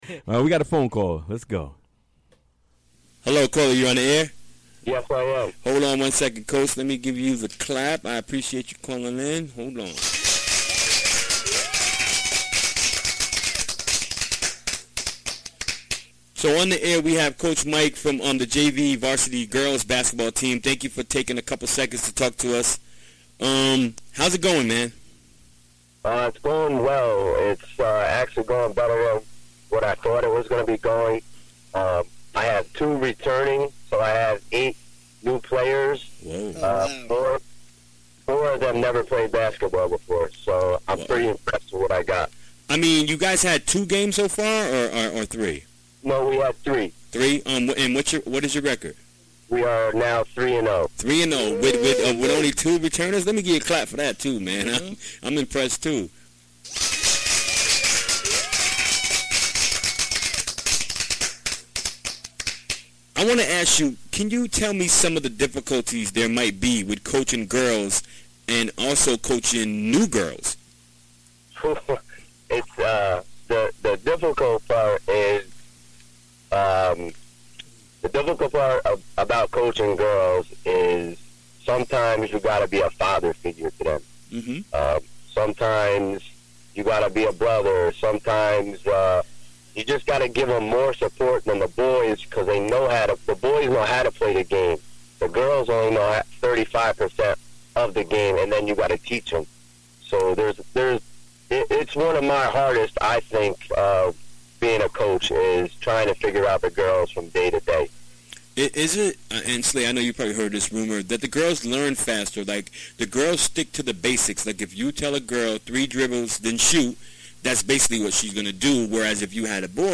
Recorded during the WGXC Afternoon Show Wednesday, December 6, 2017.